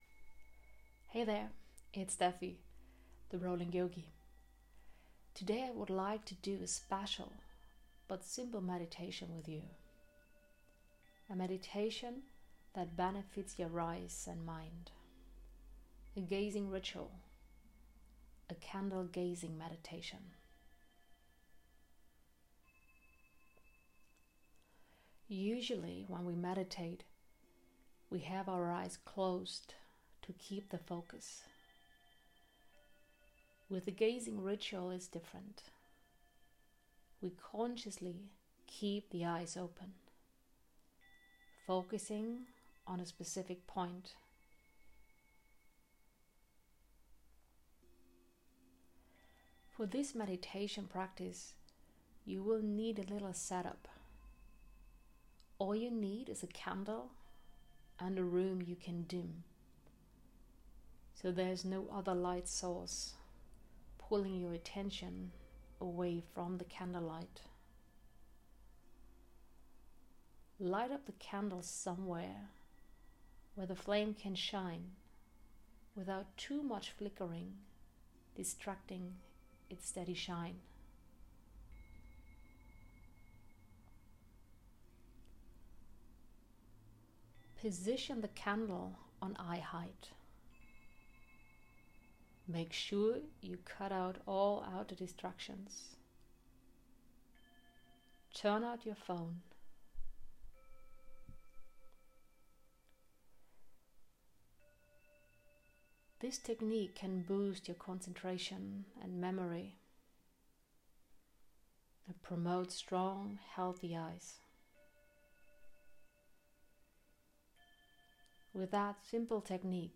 candle-gazing-meditation-the-rolling-yogi+.m4a